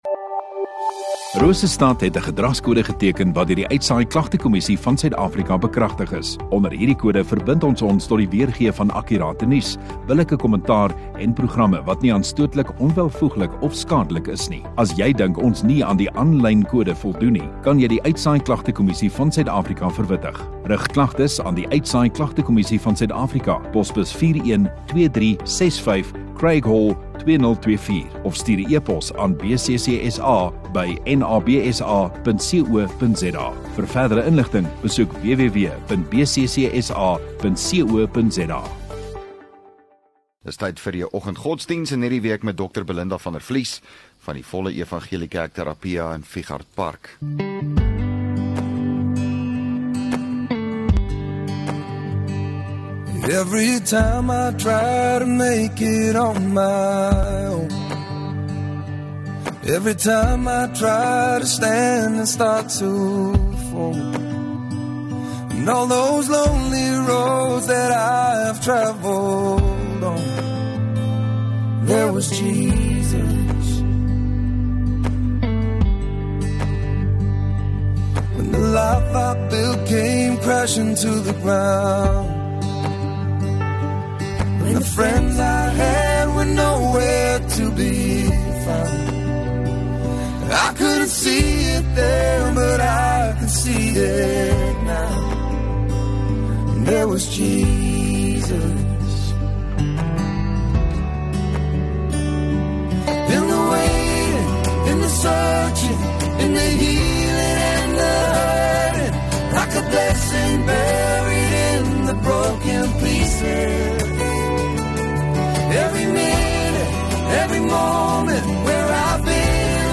12 Mar Woensdag Oggenddiens